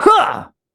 Kibera-Vox_Jump.wav